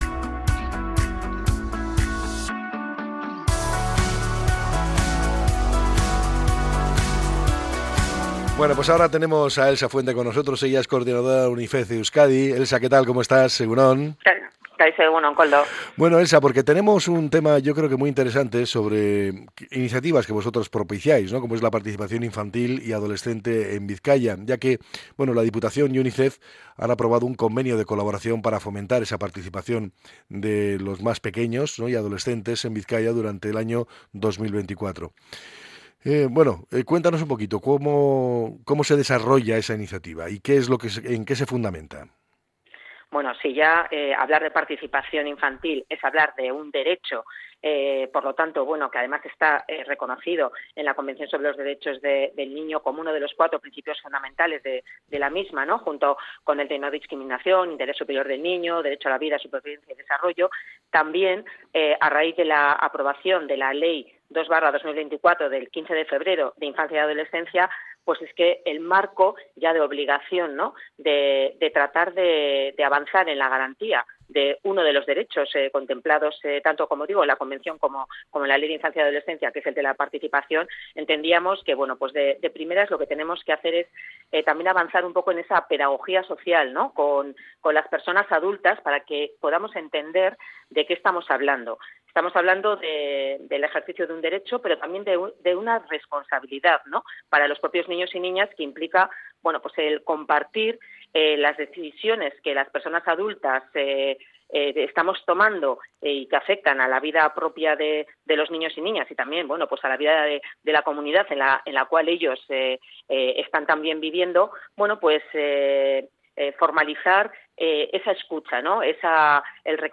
ha pasado por los micrófonos de EgunOn Bizkaia en Radio Popular-Herri Irratia para hacer hincapié en la necesidad de fomentar la participación infantil.